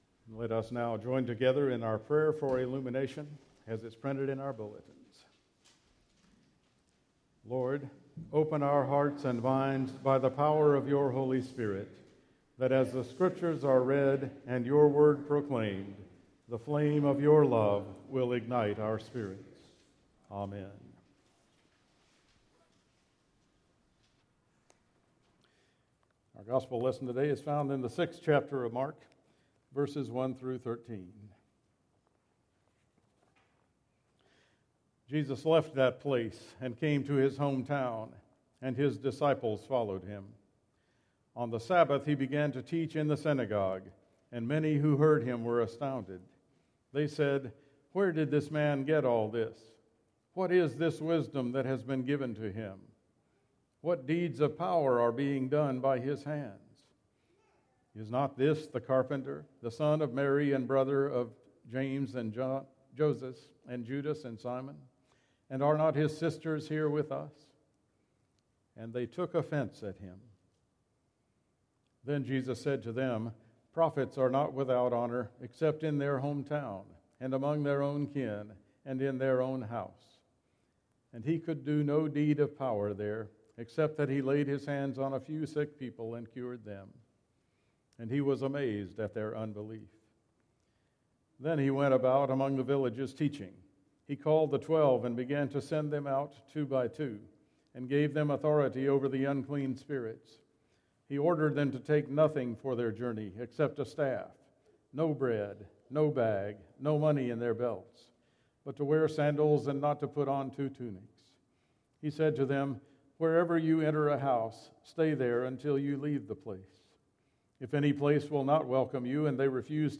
July 8 Worship Service